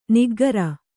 ♪ niggara